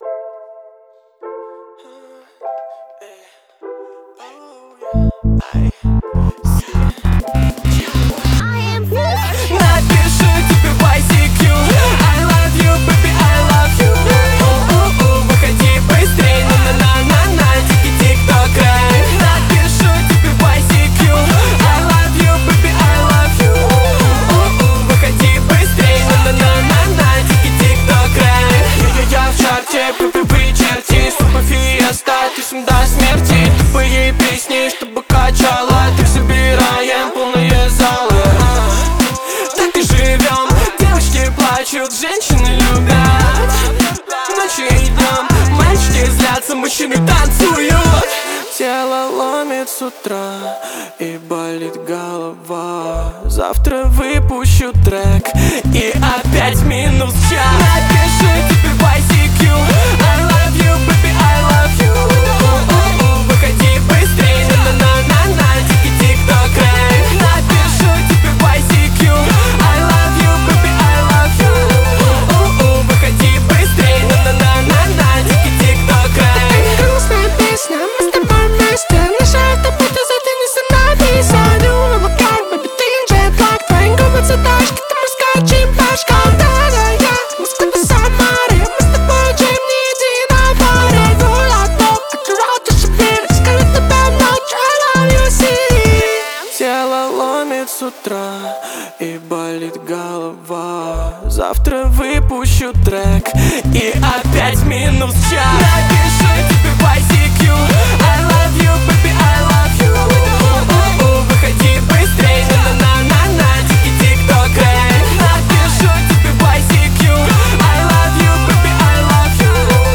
это энергичная композиция в жанре EDM